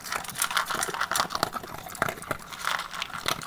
peteat.wav